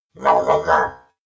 Skel_COG_VO_statement.ogg